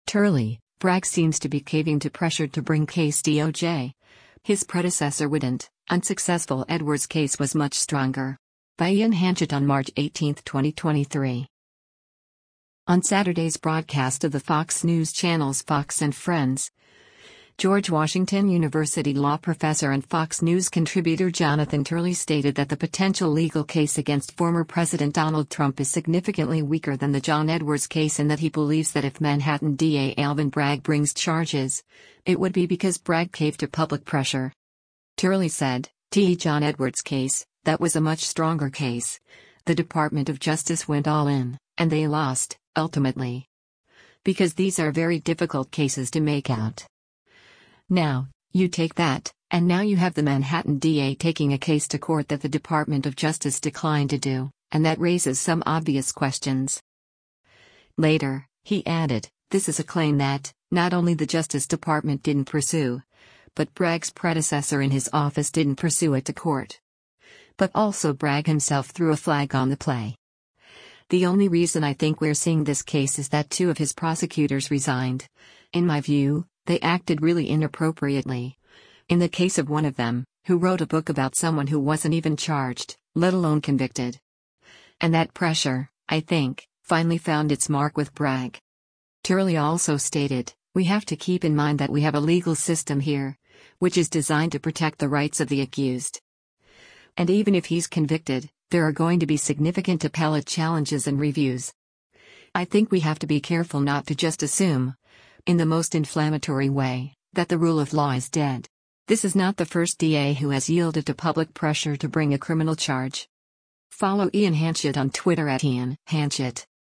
On Saturday’s broadcast of the Fox News Channel’s “Fox & Friends,” George Washington University Law Professor and Fox News Contributor Jonathan Turley stated that the potential legal case against former President Donald Trump is significantly weaker than the John Edwards case and that he believes that if Manhattan D.A. Alvin Bragg brings charges, it would be because Bragg caved to public pressure.